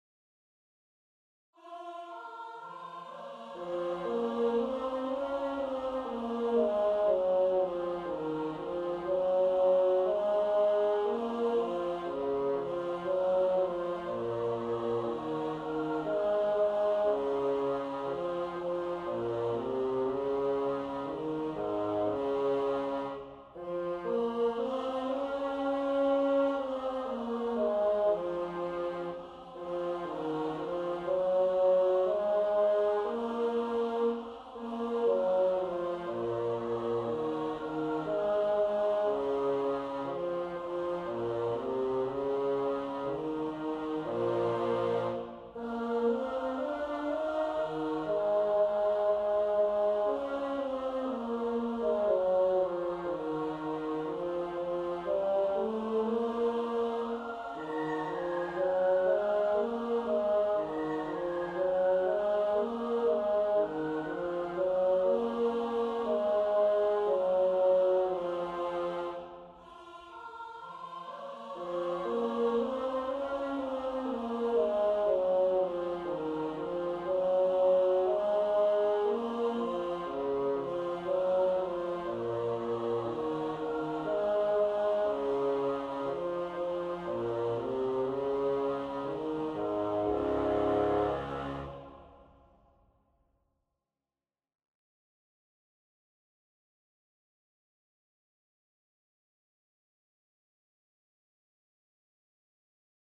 GAUDETE PRACTICE TRACKS:
4020-bass.mp3